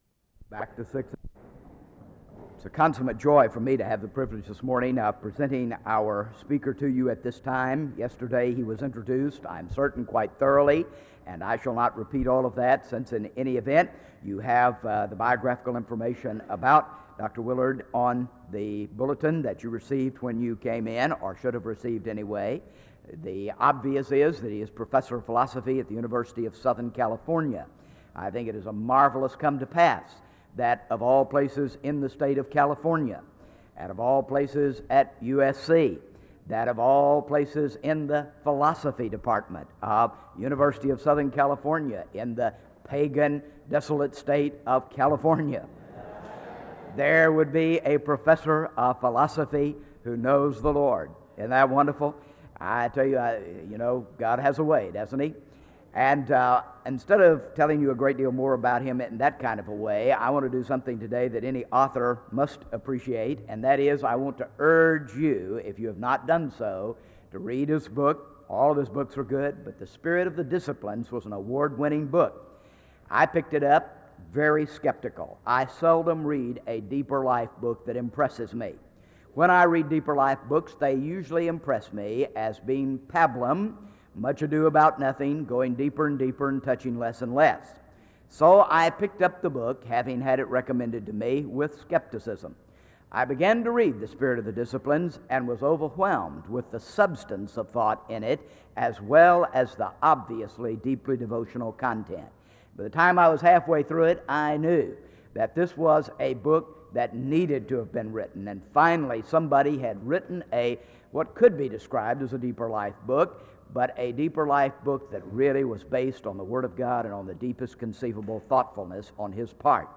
SEBTS Page Lecture - Dallas Willard September 27, 1995